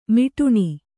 ♪ miṭuṇi